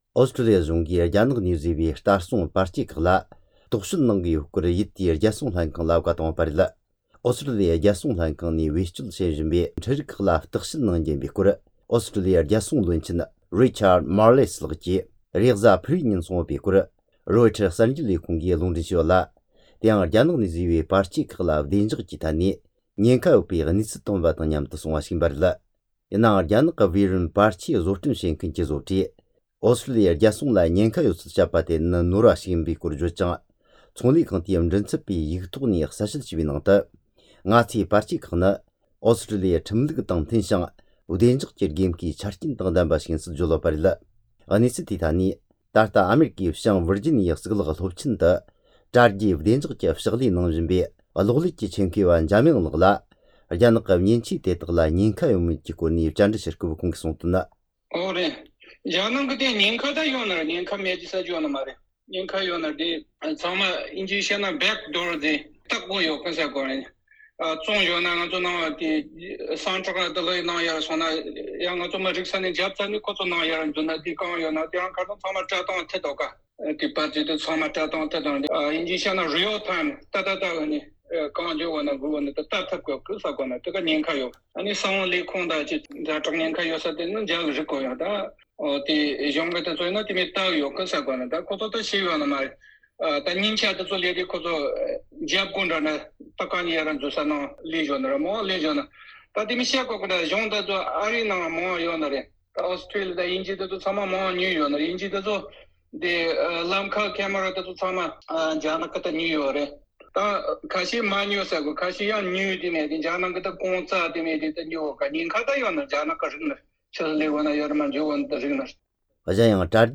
བཅར་འདྲི་དང་འབྲེལ་ཡོད་གནས་ཚུལ་ཕྱོགས་བསྡུས་བྱས་པ་ཞིག་ལ་གསན་རོགས་གནོངས།